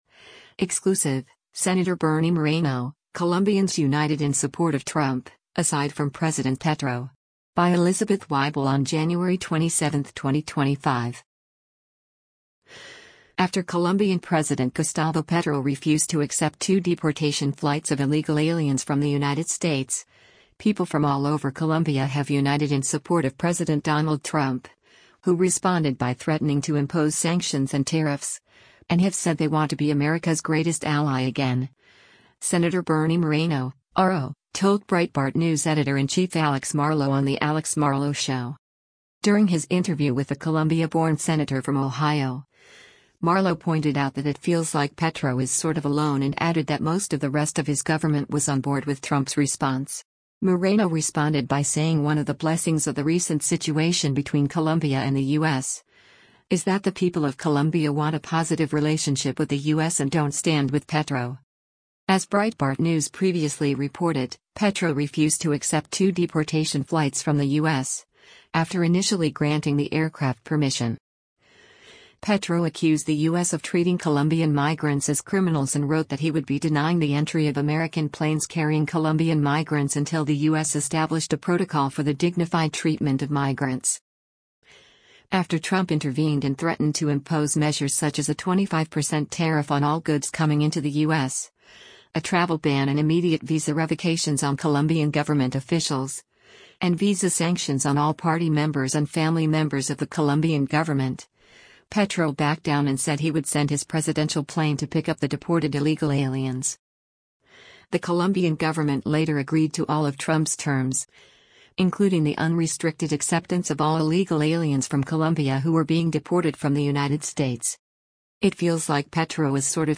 is a weekday podcast produced by Breitbart News and Salem Podcast Network.